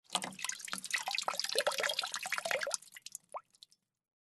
Звуки колодца
Звук ведра зачерпывающего воду